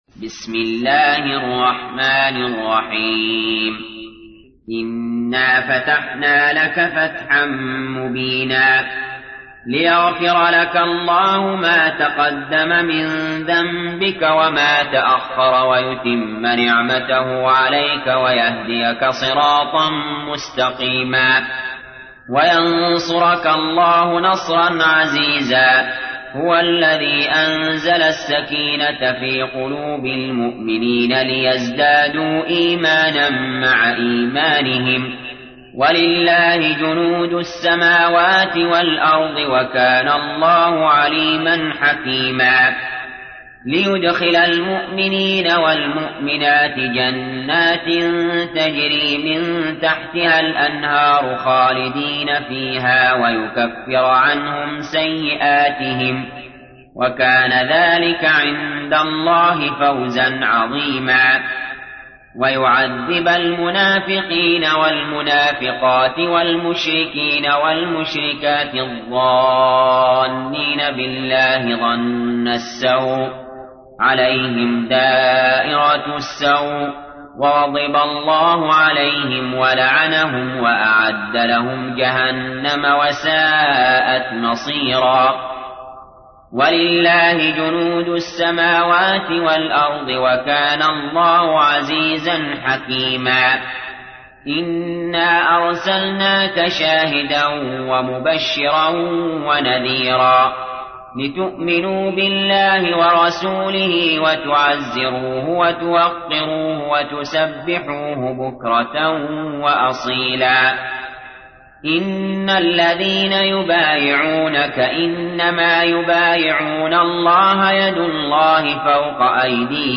تحميل : 48. سورة الفتح / القارئ علي جابر / القرآن الكريم / موقع يا حسين